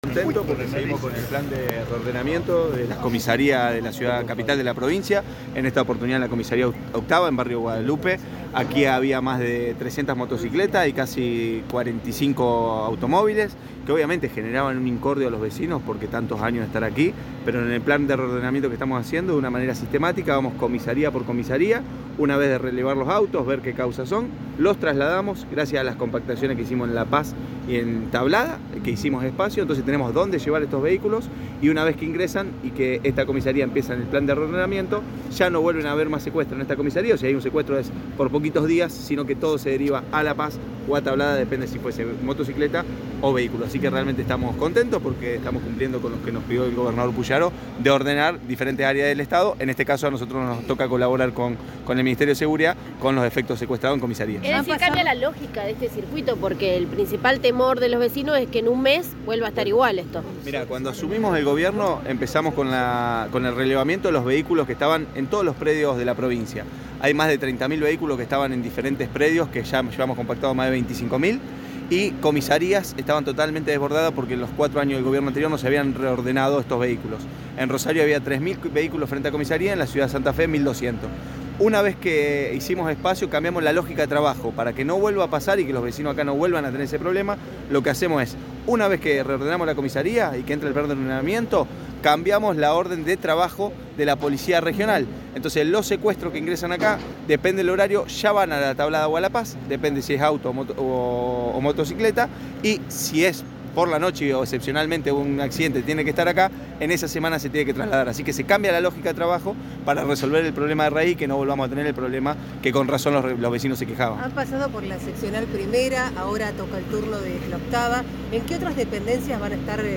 Declaraciones de Matías Figueroa Escauriza, secretario de Registros